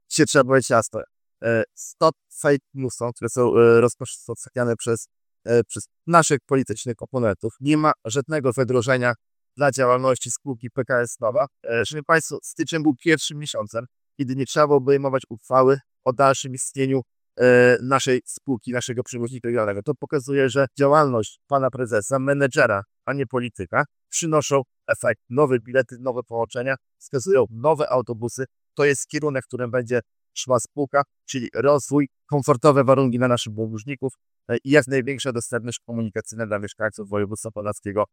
– Stop fake newsom, które są rozpowszechniane przez naszych politycznych oponentów – mówił marszałek województwa podlaskiego Łukasz Prokorym, na konferencji zwołanej w sprawie wyjaśnienia sytuacji w PKS NOVA.